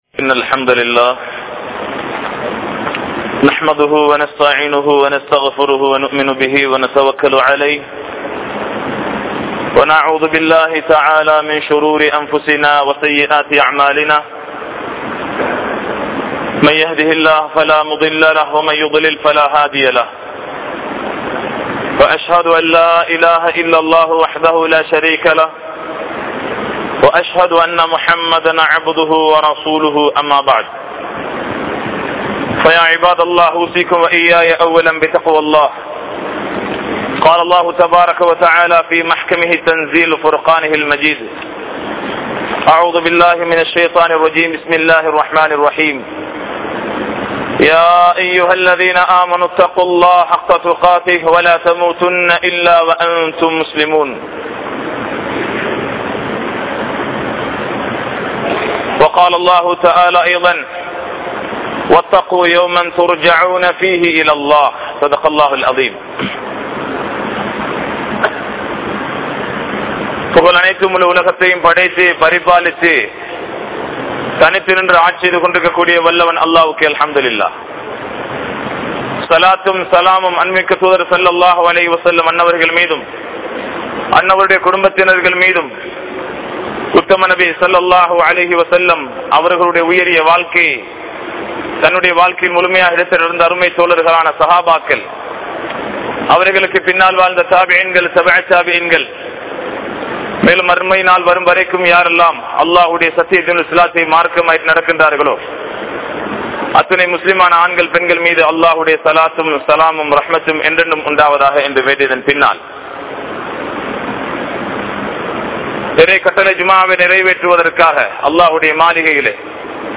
Allah`vai Maranthu Vidaatheerhal | Audio Bayans | All Ceylon Muslim Youth Community | Addalaichenai